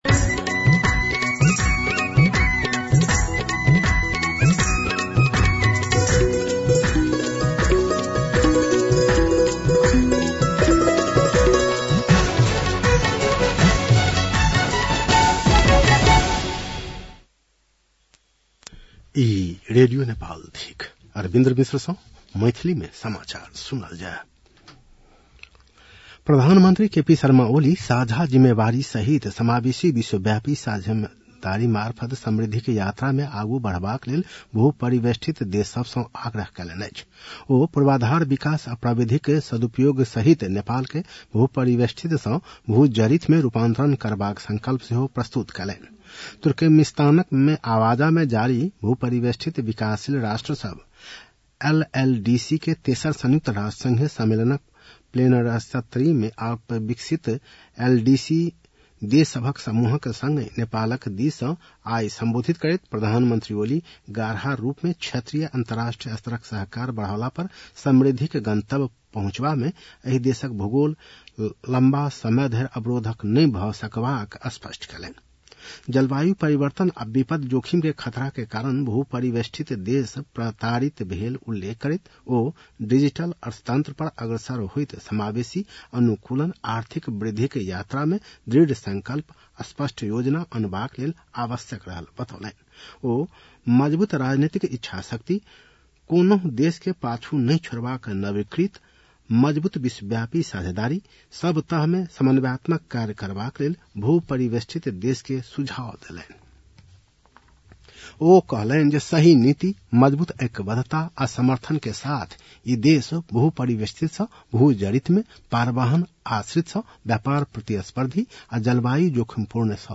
मैथिली भाषामा समाचार : २० साउन , २०८२